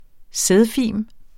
Udtale [ ˈsεðˌfiˀm ]